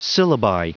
Prononciation du mot syllabi en anglais (fichier audio)
Prononciation du mot : syllabi